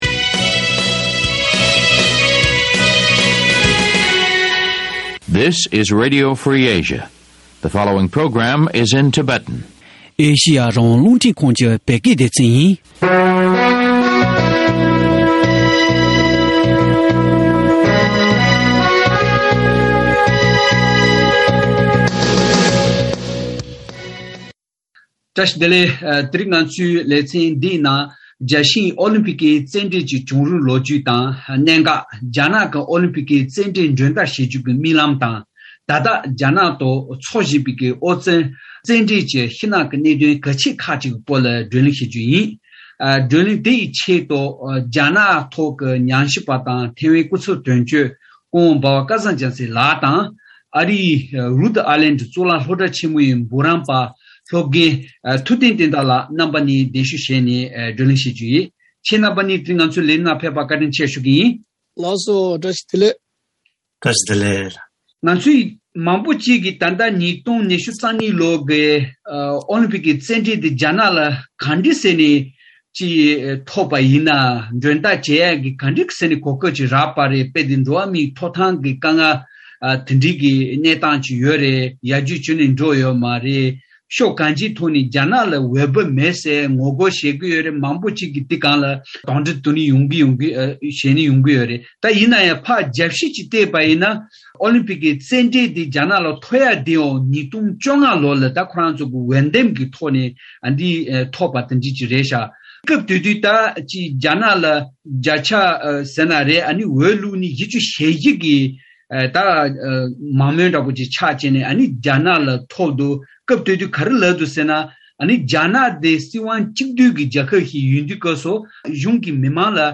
གླེང་མོལ་ཞུས་པ། ཨེ་ཤེ་ཡ་རང་དབང་རླུང་འཕྲིན་ཁང་།